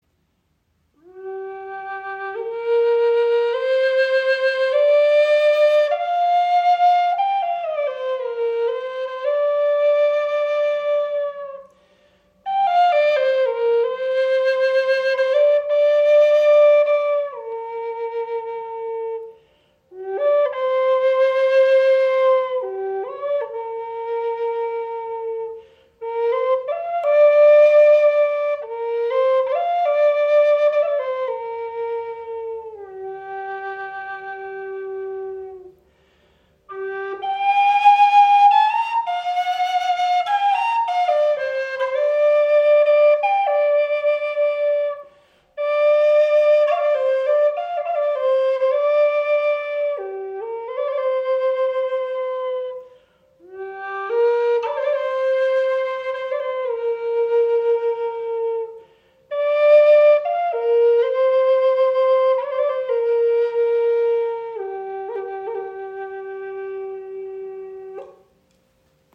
Handgefertigte Gebetsflöte aus Curly Walnut mit Raben-Windblock aus Purple Heart in G-Moll. Erdiger, klarer Klang – ideal für Meditation, Rituale & intuitive Klangarbeit.
Walnussholz ist bekannt für seinen erdigen, warmen und zugleich lebendigen Klang, der besonders im mittleren Tonbereich eine schöne Präsenz entfaltet.
In G-Moll gestimmt, entfaltet sie einen vibrierenden, ausgewogenen Ton, der leicht anspricht, stabil trägt und sowohl für Meditation, Rituale als auch freies, intuitives Spiel geeignet ist.
Ihre präzise Intonation, das weiche Ansprechverhalten und der volle Klang machen sie zu einem treuen Begleiter auf jeder musikalischen und spirituellen Reise.